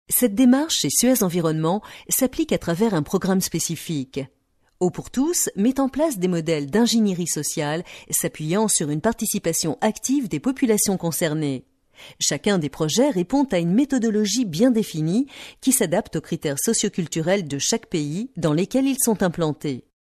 French voiceover example
A sample of the voice of one of our French voiceover artists.